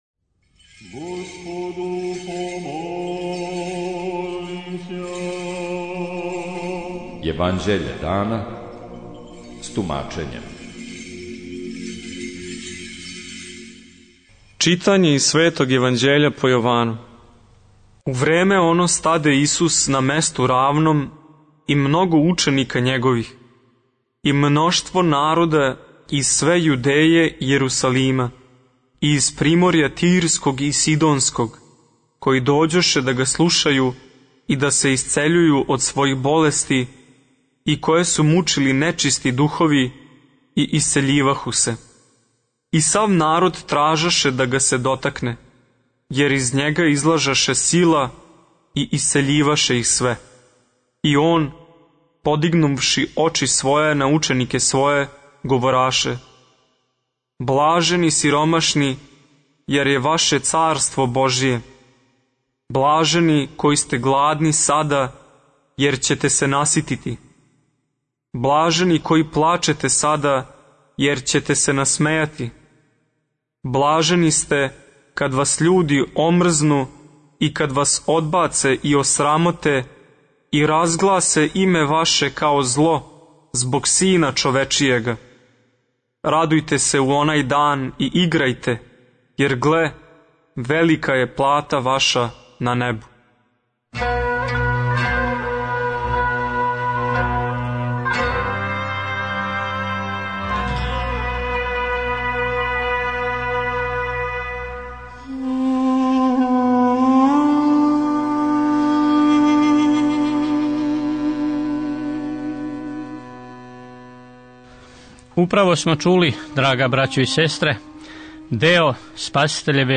Читање Светог Јеванђеља по Матеју за дан 18.01.2026. Зачало 5.